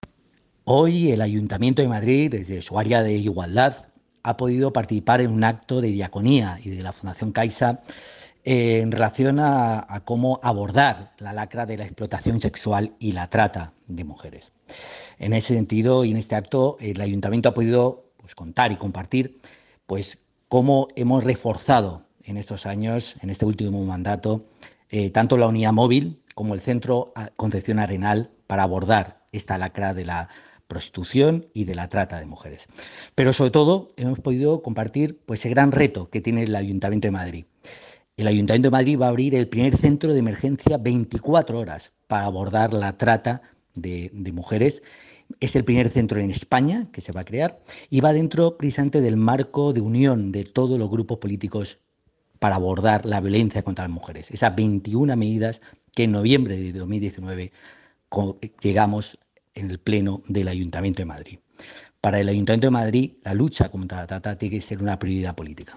Durante su participación en la mesa institucional ‘Claves para desincentivar el consumo de prostitución y prevenir la explotación sexual y la trata’
Nueva ventana:Declaraciones de Aniorte sobre el Centro Municipal de Emergencia para la Atención a Víctimas de Trata